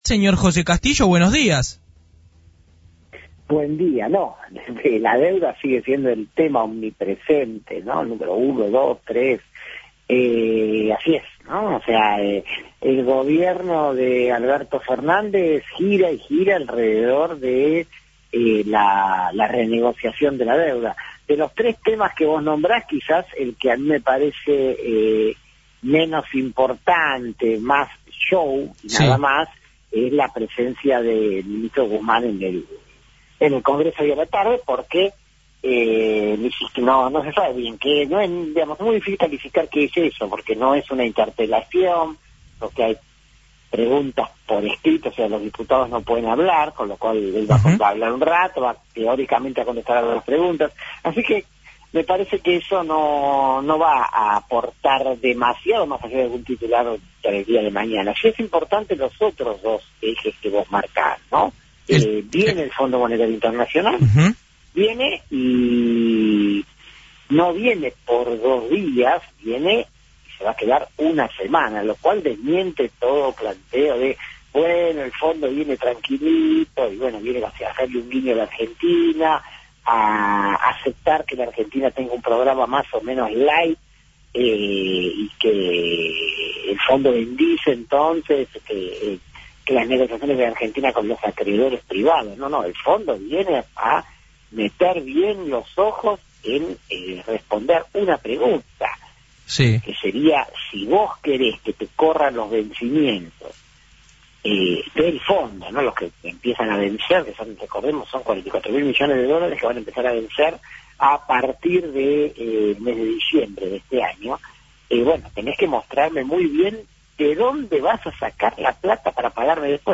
En dialogo con FRECUENCIA ZERO